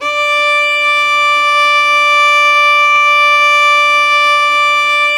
Index of /90_sSampleCDs/Roland - String Master Series/STR_Violin 4 nv/STR_Vln4 % marc